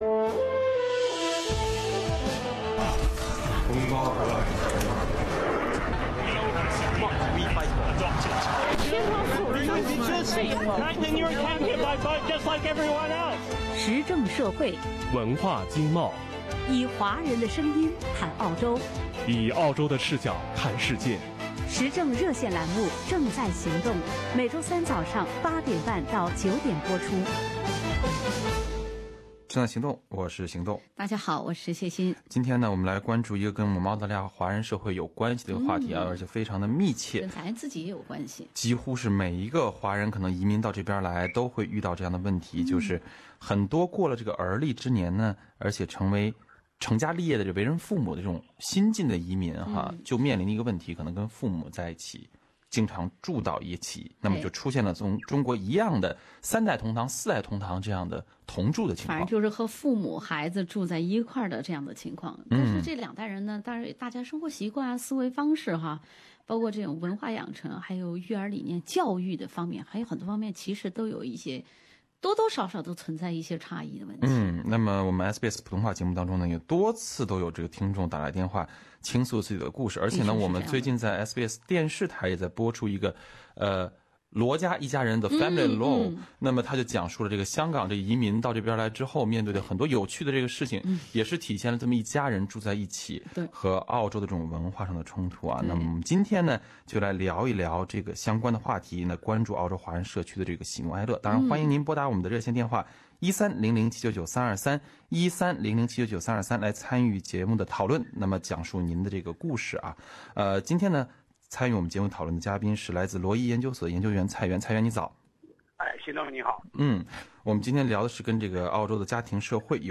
但两代人在思维方式、生活习惯、文化养成以及育儿理念上的差异造成家庭矛盾时有发生。在我们SBS普通话节目中也多次会有听众打来电话倾诉自己的故事。